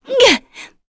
Shamilla-Vox_Attack1.wav